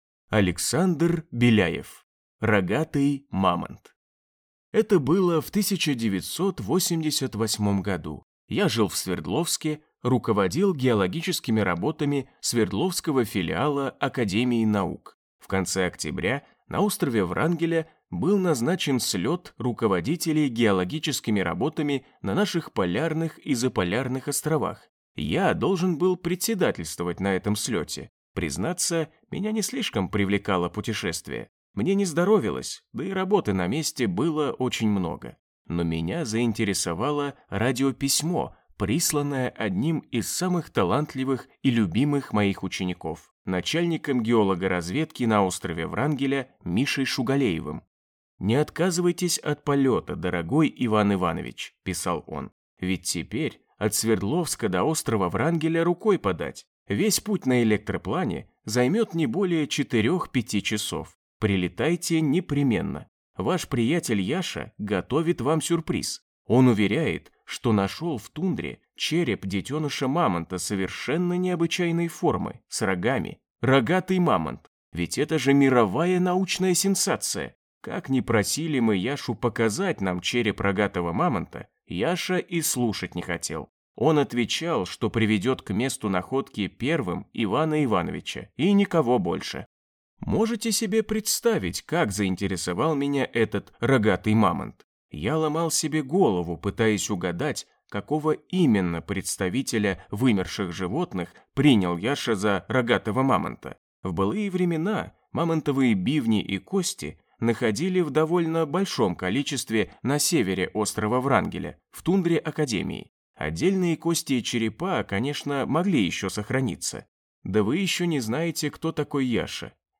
Аудиокнига Рогатый мамонт | Библиотека аудиокниг